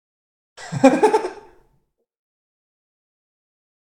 Weird Male Laugh Sound Button - Free Download & Play
Sound Effects Soundboard1,997 views